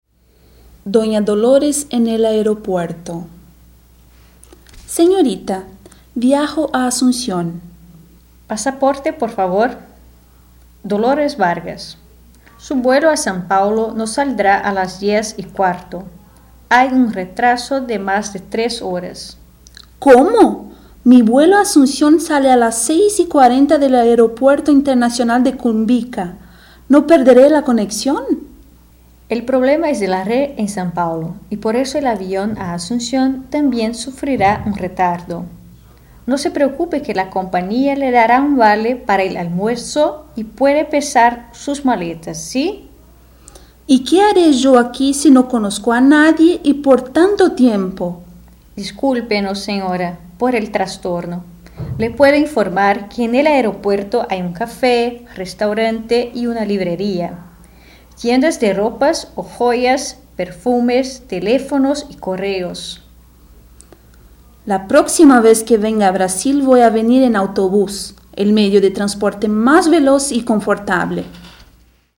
Description: Áudio do livro didático Língua Espanhola I, de 2008. Diálogo no aeroporto.